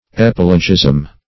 Search Result for " epilogism" : The Collaborative International Dictionary of English v.0.48: Epilogism \E*pil"o*gism\, n. [Gr.